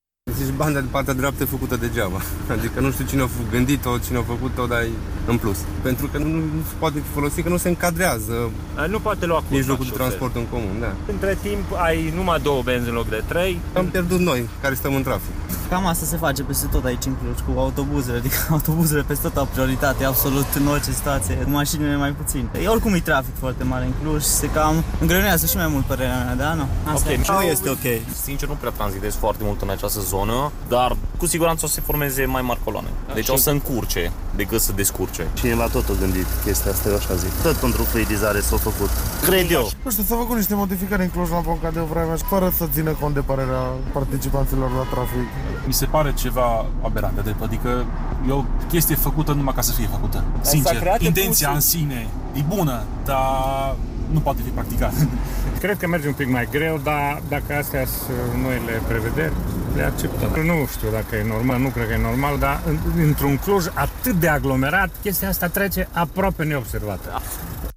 Am discutat cu mai mulți șoferi aflați în trafic pe strada Barițiu. Unii sunt iritați de inițiativă, câtă vreme banda de bus nu e, mai exact nu poate fi folosită, în timp ce alții consideră că intenția din spate nu e rea dar necesită îmbunătățiri.